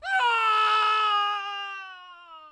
manScreamFall.wav